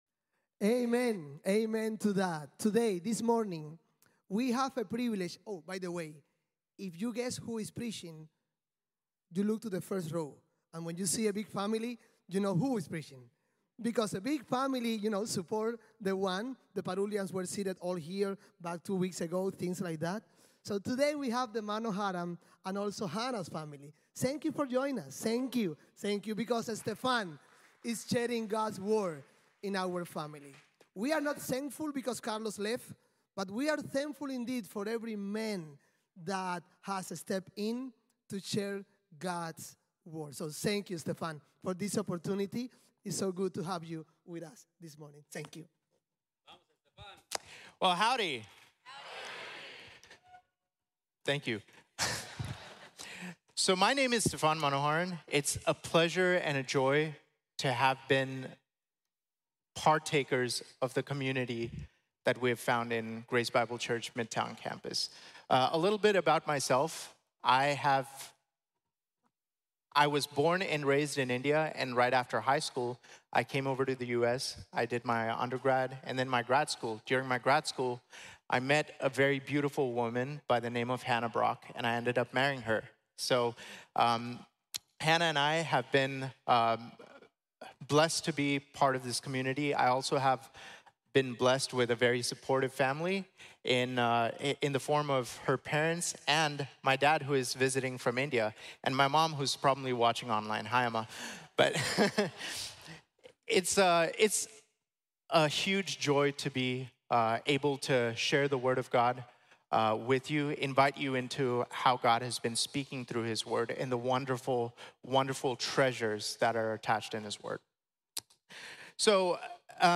El Buen Pastor | Sermón | Iglesia Bíblica de la Gracia